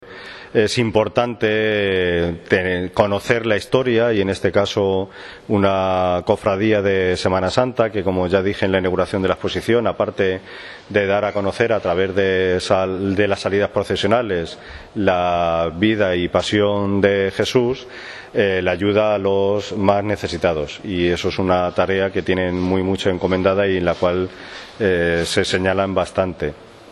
La Confianza’ acogió ayer la conferencia enmarcada dentro de la celebración de los actos del primer centenario de la cofradía.